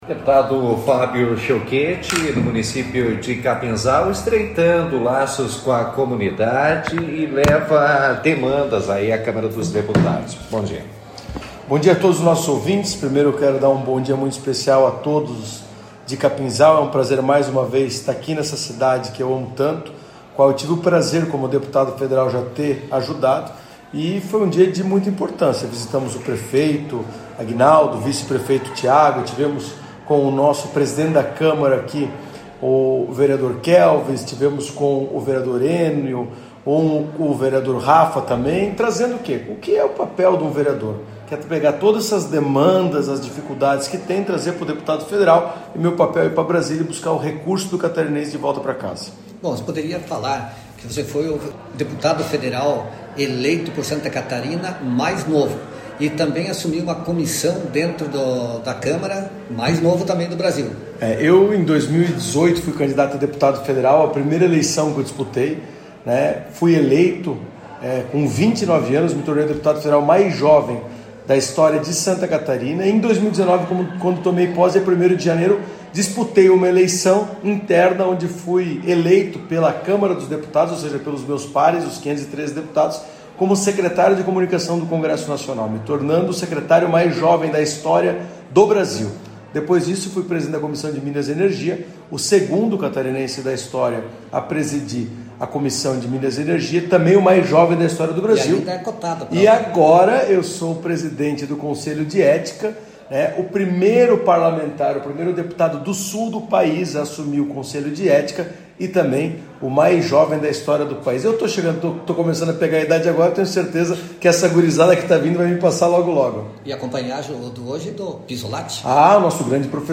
O deputado federal Fábio Schiochet visitou Capinzal na quarta-feira, cumprindo agenda com o presidente da Câmara de Vereadores, Kelvis Borges e os vereadores Enio José Paggi e Rafael Edgar Tonial. Durante a entrevista, ele destacou a importância da relação entre vereadores e parlamentares para garantir recursos e atender as demandas do município.